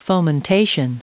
Prononciation du mot fomentation en anglais (fichier audio)